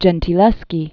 (jĕntē-lĕskē), Artemisia 1593?-1652?